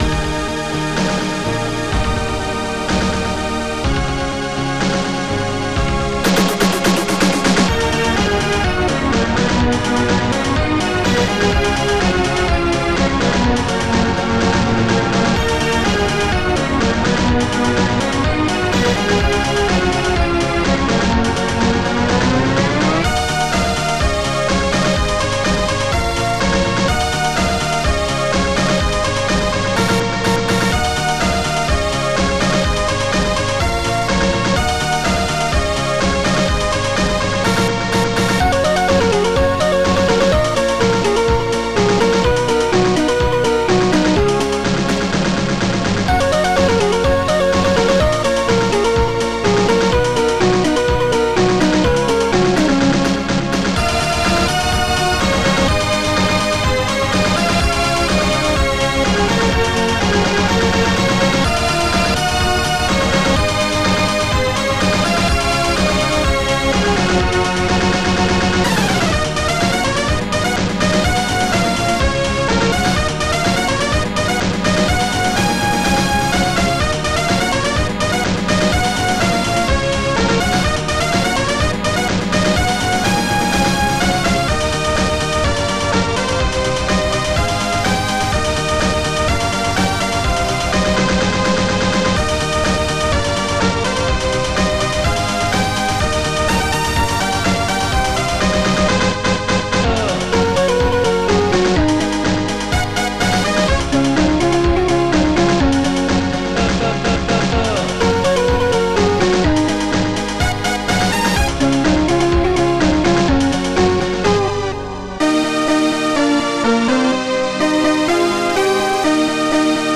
Protracker Module  |  1992-04-20  |  112KB  |  2 channels  |  44,100 sample rate  |  2 minutes, 10 seconds
Protracker and family